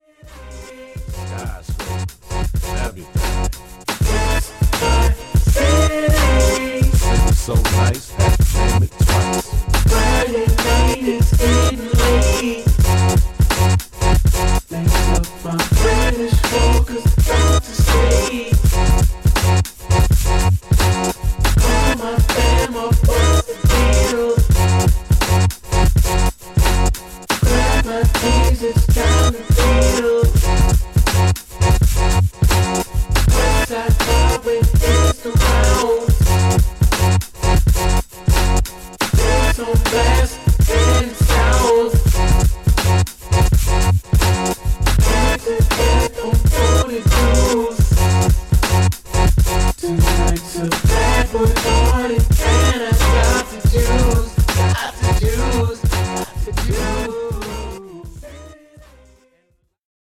ホーム ｜ CROSSOVER / BREAKBEATS > CROSSOVER